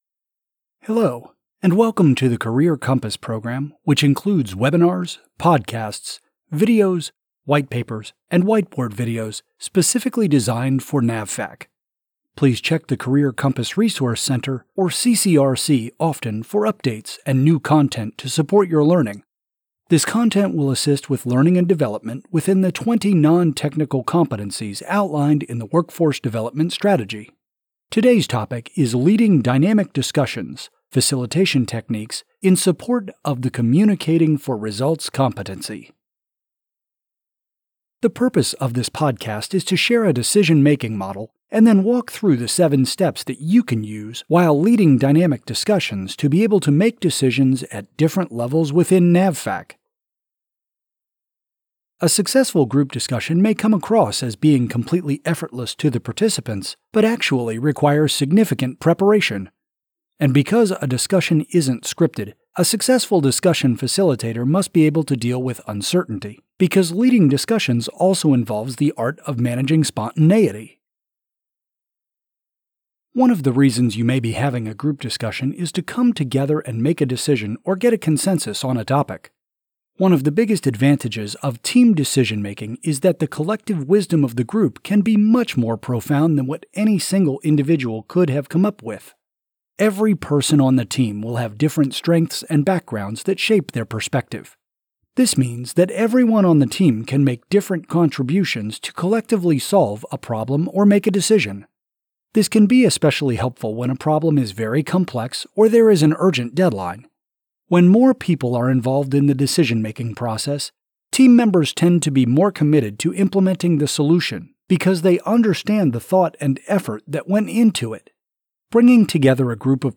These 5 – 10 minute podcasts include facilitated discussions on select competency-related topics. They contain tips and techniques listeners can learn and quickly apply on-the-job.